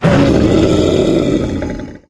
izlome_death_2.ogg